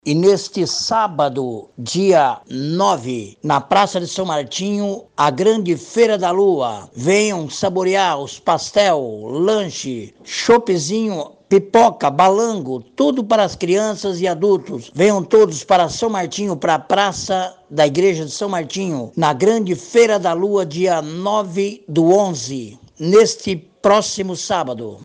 O vereador Eugênio Serpeloni convida a população para a Feira da Lua: